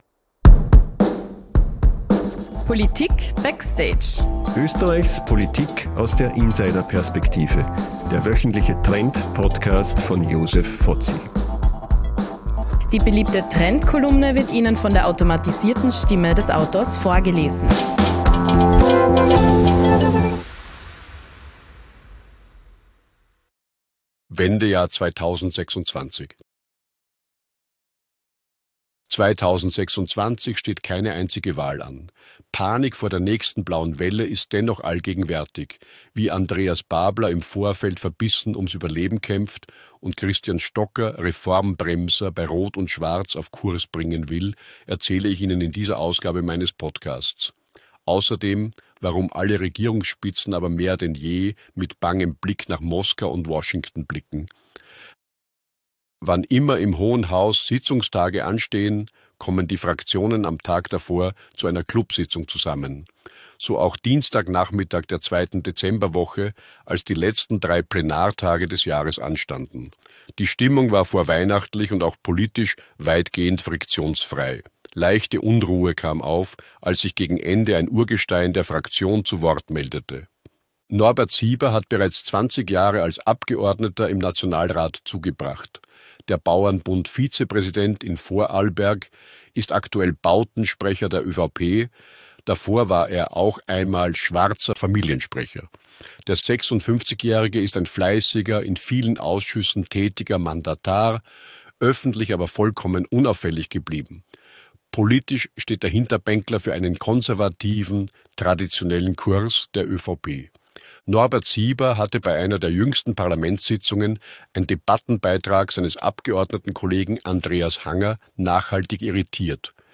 Interviews, Best Practices und Know-How für Ihren persönlichen und wirtschaftlichen Erfolg.